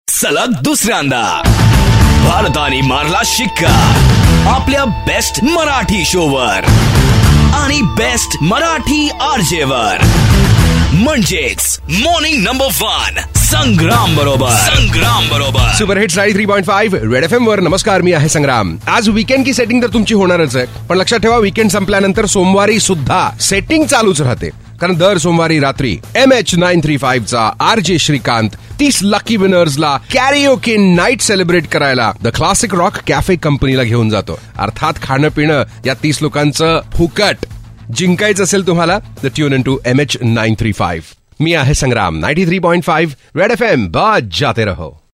India Ka no. 1 Marathi Radio Show.